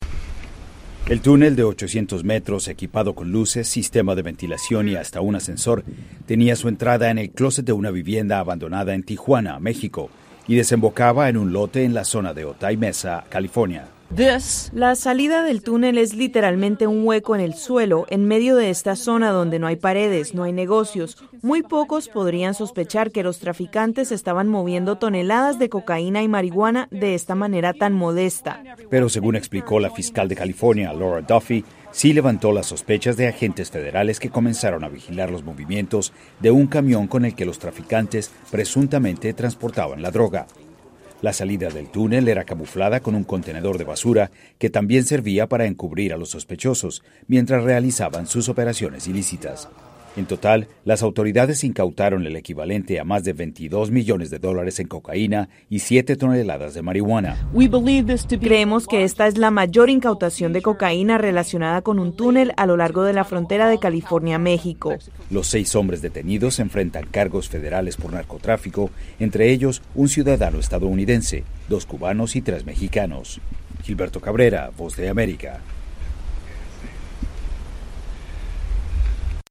Agentes federales estadounidenses desmantelaron el que sería el túnel más largo, utilizado para el tráfico de drogas, entre México y Estados Unidos. Seis hombres fueron arrestados durante el operativo, además de una cantidad de droga sin precedentes. Con el informe